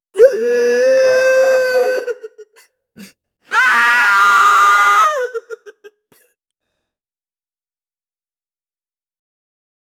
funny-cartoon-crying-soun-w3uunwkt.wav